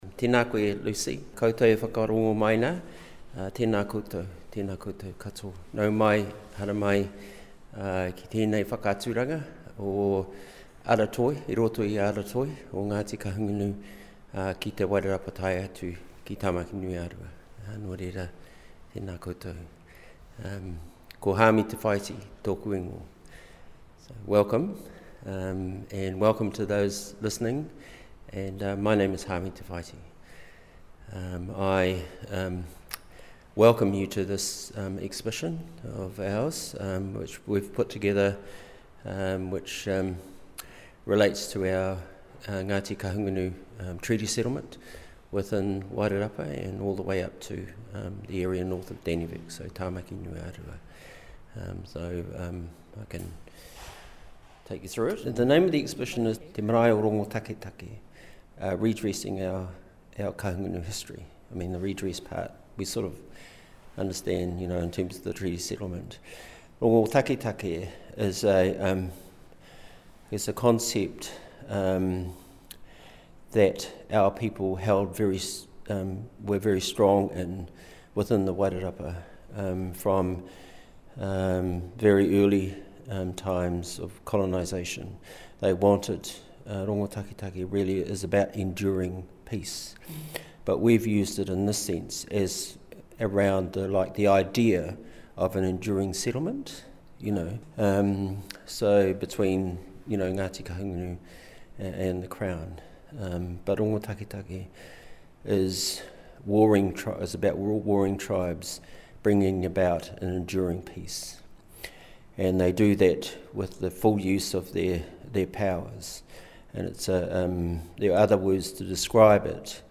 Here is a recording of my visit. A slightly shorter version of this interview was originally played on May’s edition of ReCooper8 on Arrow FM 92.7. https